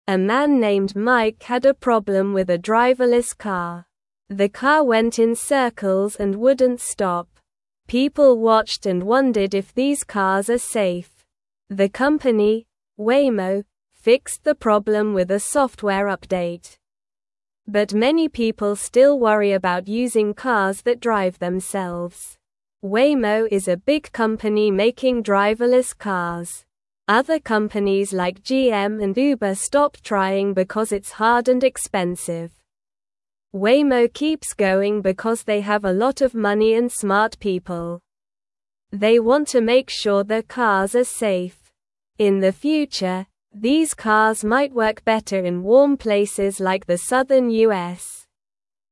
Slow
English-Newsroom-Beginner-SLOW-Reading-Driverless-Cars-Safe-or-Not-for-Everyone.mp3